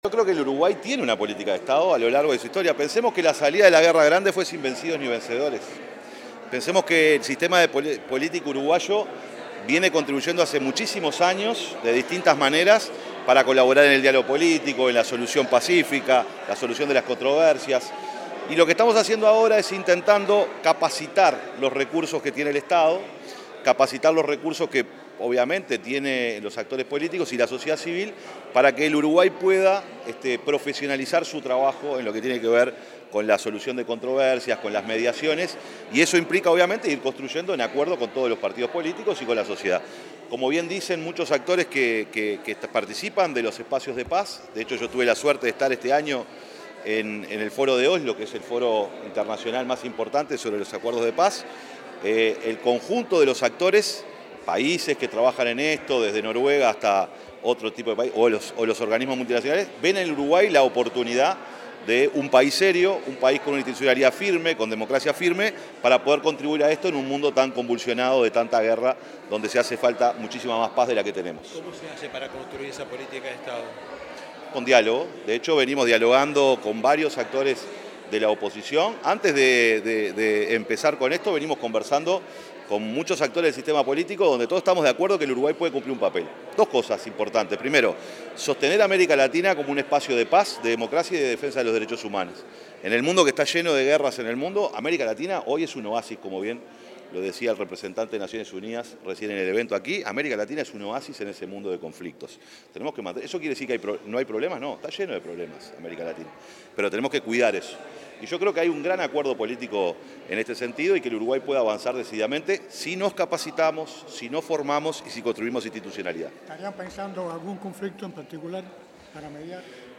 Declaraciones del secretario de Presidencia, Alejandro Sánchez
En el marco del Lanzamiento: Iniciativa Uruguay promotor de paz, el secretario de Presidencia, Alejandro Sánchez realizó declaraciones.